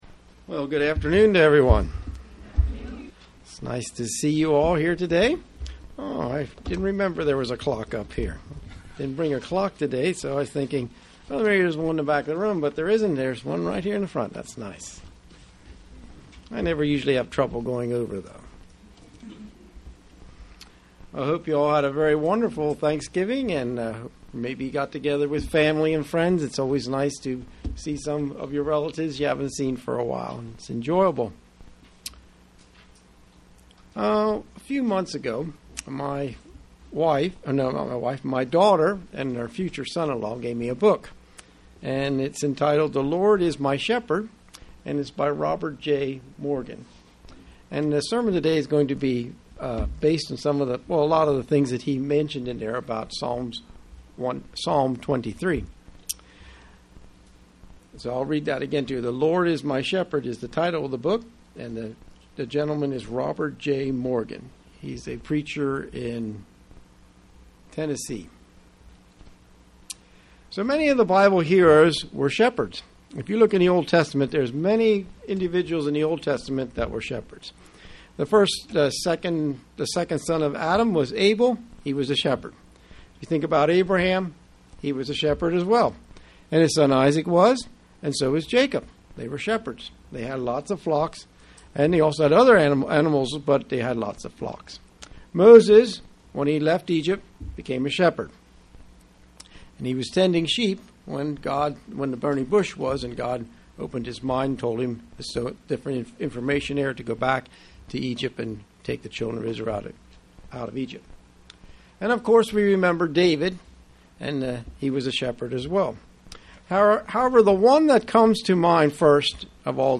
Given in York, PA
UCG Sermon Studying the bible?